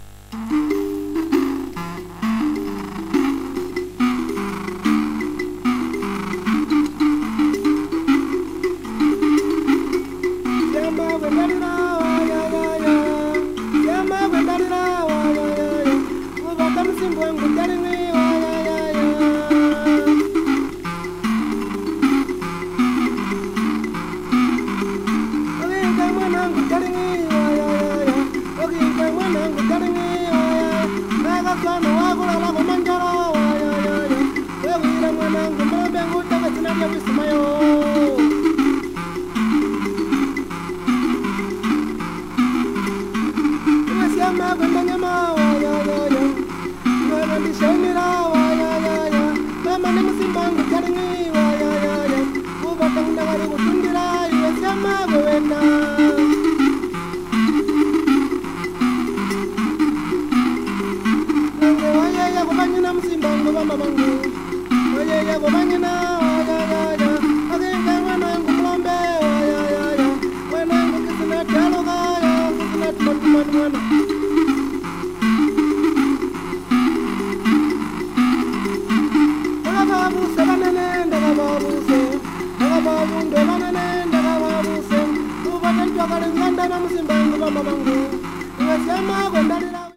イイです！